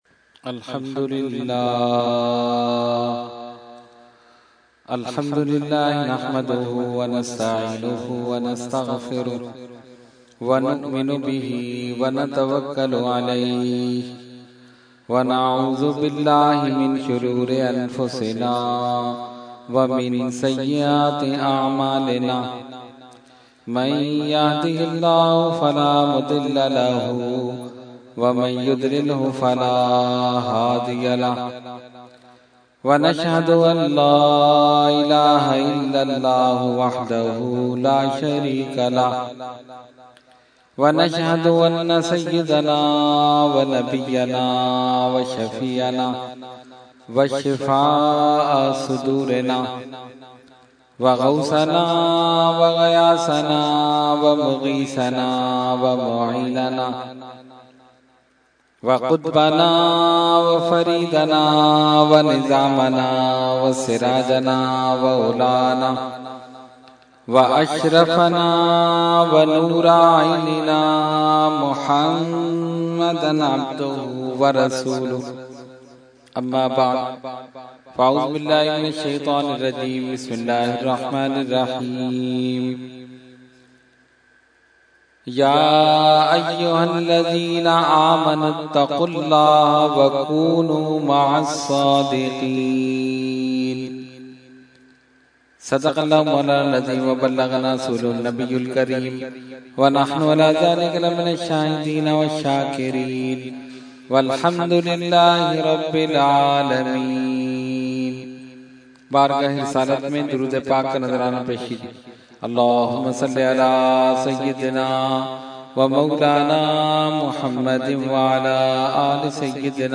Category : Speech | Language : UrduEvent : Mehfil 11veen Liaqatabad 17 February 2014